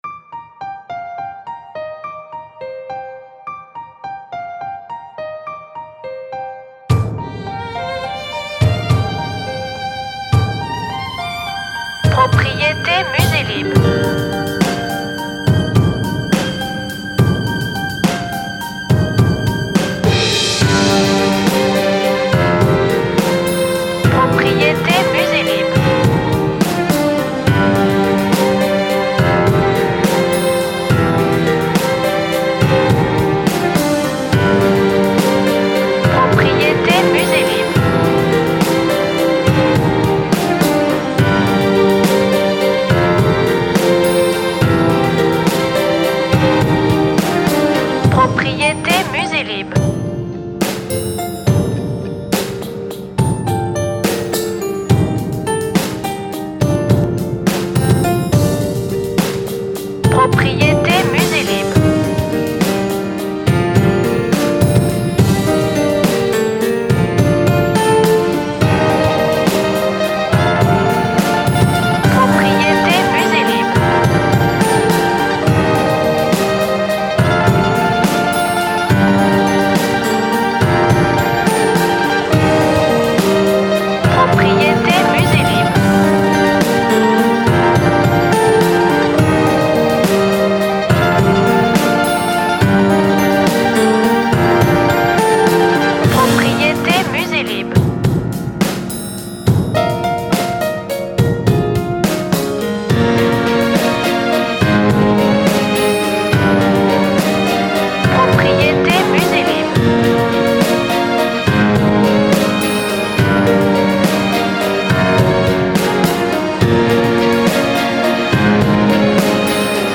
Epopée classique en 3 temps aux allures post rock!